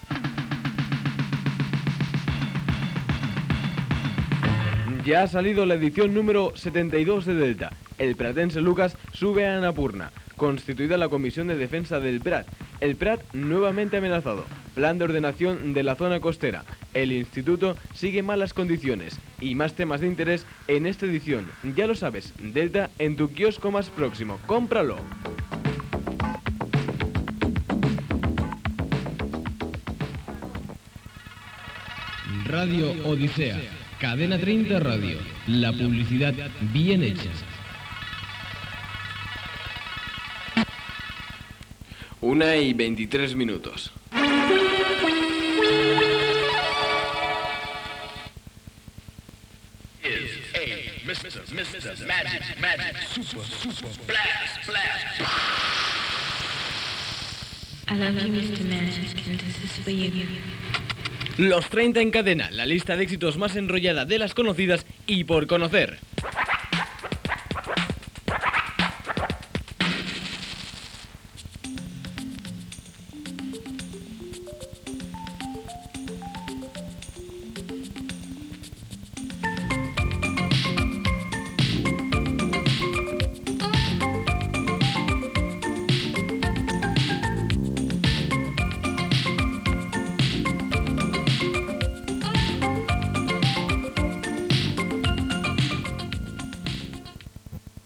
Publicitat, indicatiu de l'emissora, hora, indicatiu del programa i música.
FM